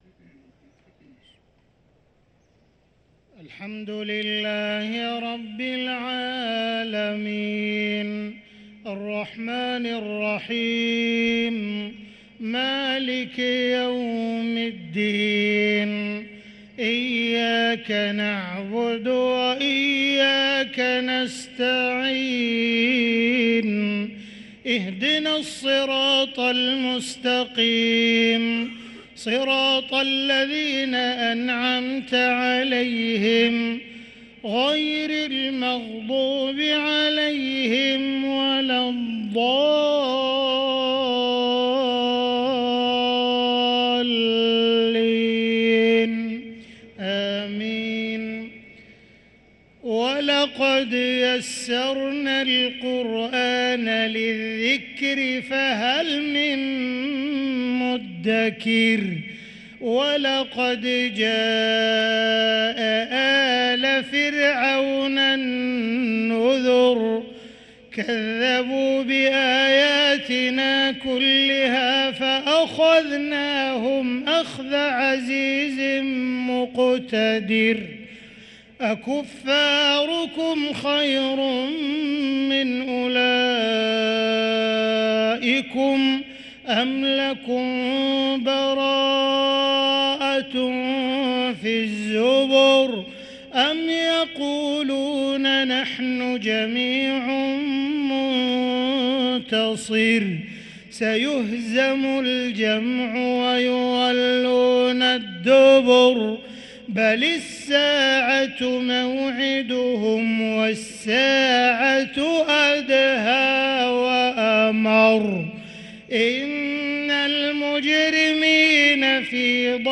صلاة المغرب للقارئ عبدالرحمن السديس 28 رمضان 1444 هـ
تِلَاوَات الْحَرَمَيْن .